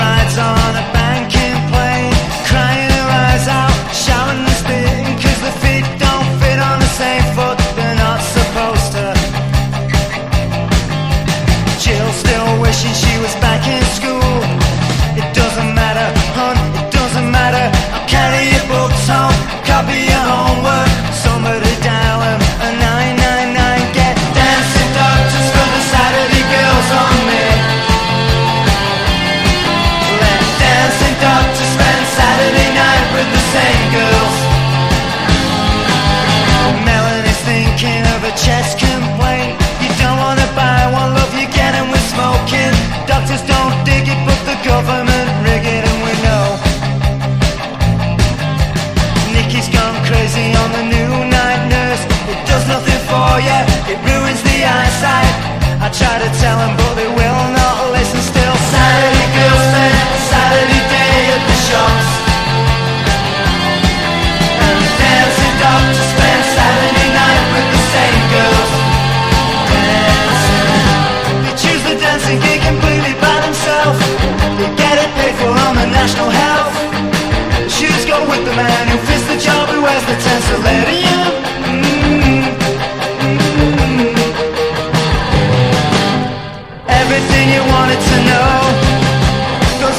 初期PUNK / POWER POP